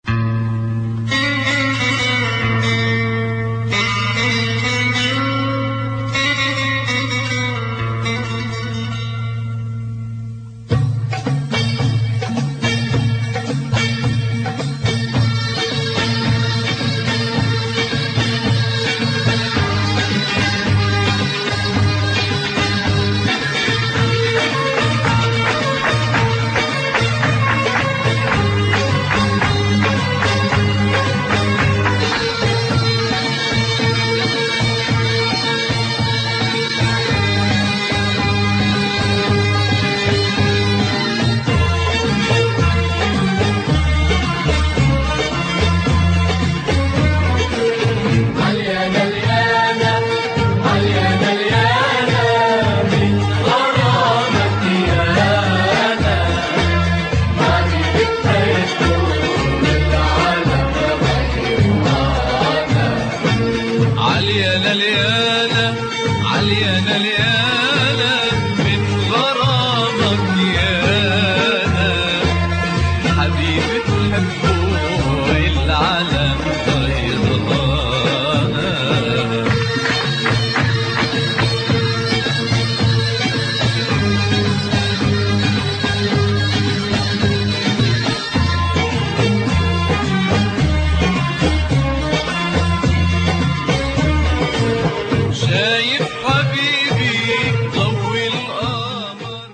Crazy oriental beats here